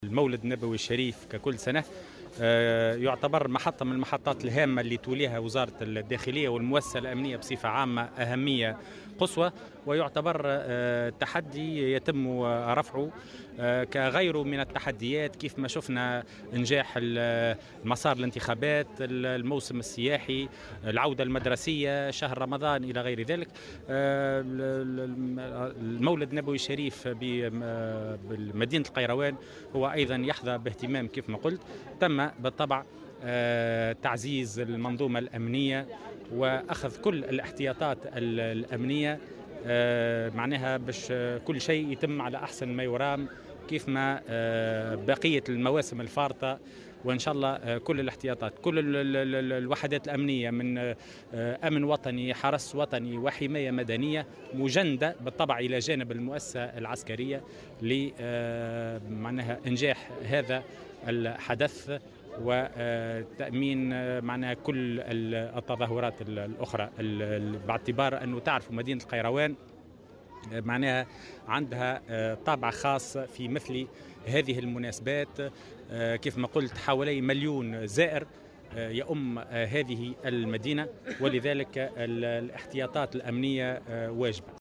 أكد وزير الداخلية، هشام الفراتي في تصريح اليوم لمراسل "الجوهرة أف أم" على هامش زيارته لولاية القيروان، أن جميع الوحدات الأمنية بمختلف أسلاكها مجنّدة لإنجاح الاحتفالات بالمولد النبوي الشريف.